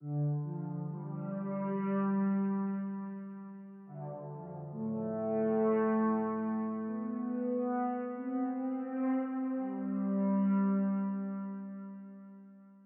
描述：低沉的低音垫，有不祥的感觉。
声道立体声